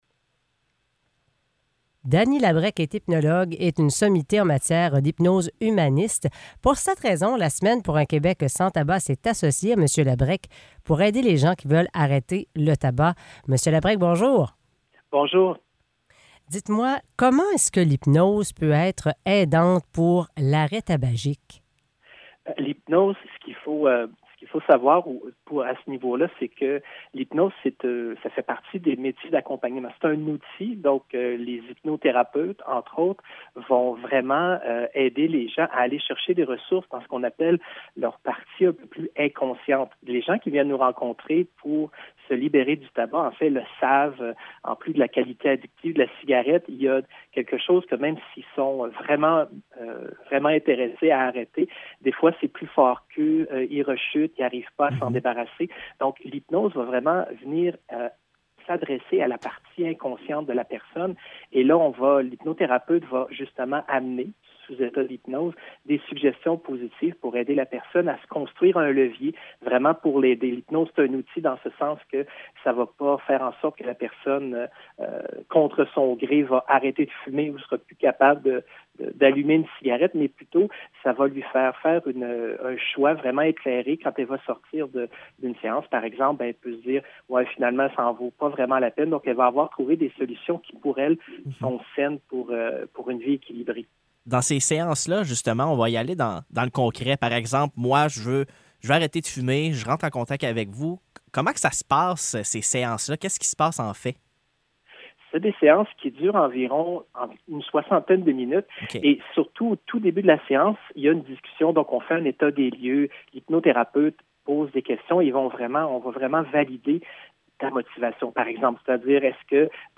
Entrevue sur la Semaine pour un Québec sans tabac